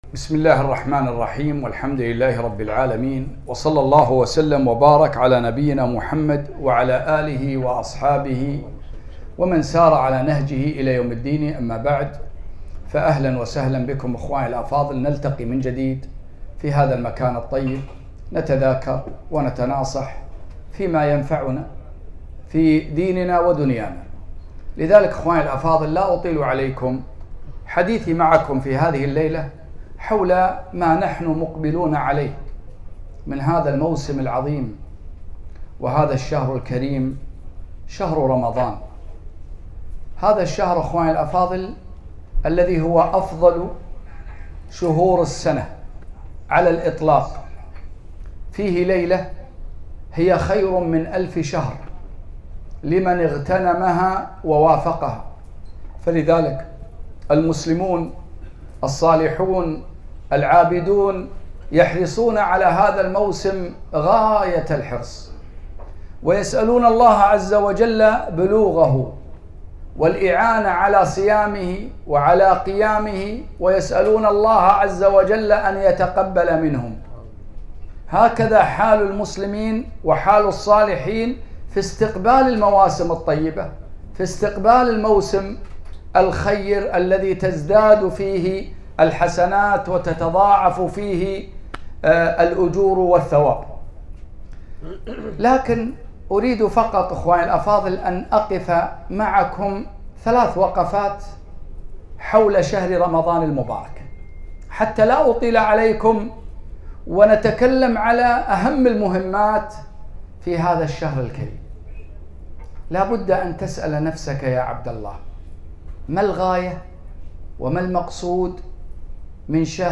كلمة - بـمـاذا نـسـتـقـبـل رمـضـــان